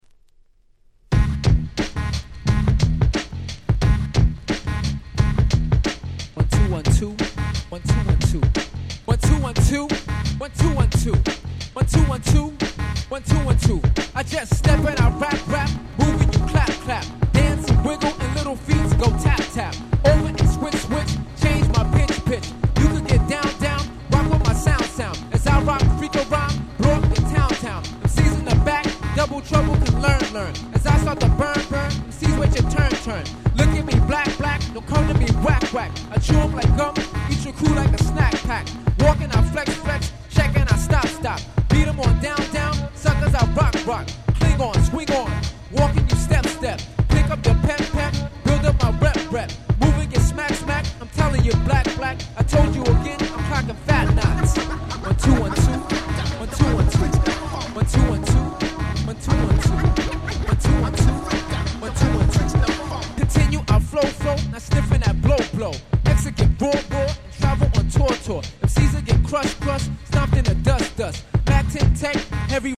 93' Nice Hip Hop !!
93年の作品と言う事もあり、80年代のFunkyな作風とは打って変わって幾分Dopeな感じに。